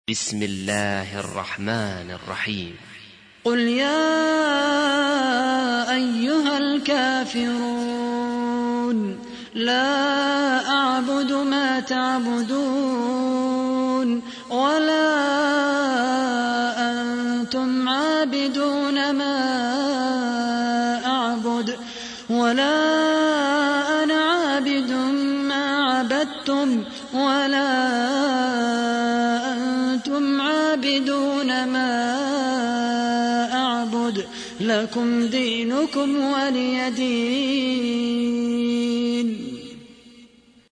تحميل : 109. سورة الكافرون / القارئ خالد القحطاني / القرآن الكريم / موقع يا حسين